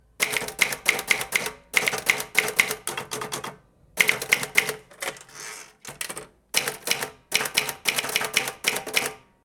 Teclear en una máquina de escribir 2
máquina de escribir
teclear
Sonidos: Oficina